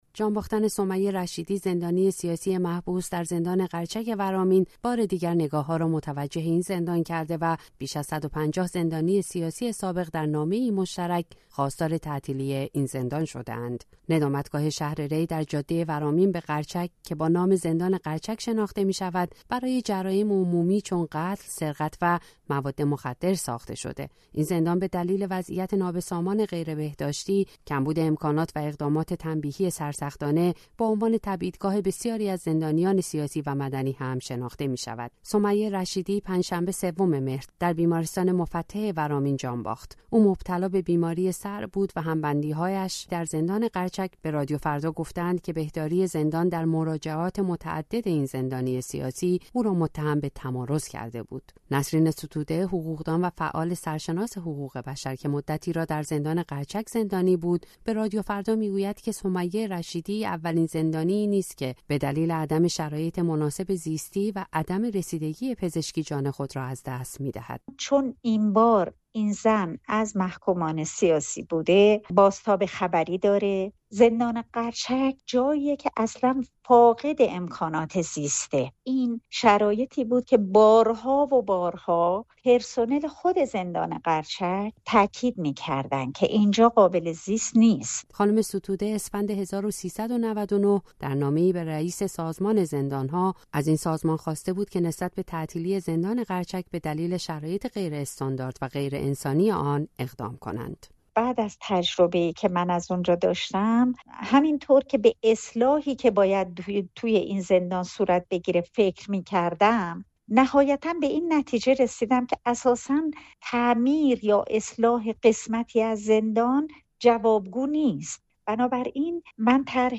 در این گزارش ویژه در گفتگو با زندانیان سابق قرچک به وضعیت حاکم بر این زندان پرداخته‌ایم.